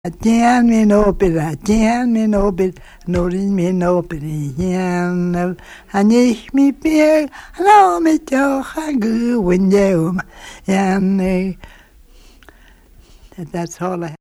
Mouth Vocal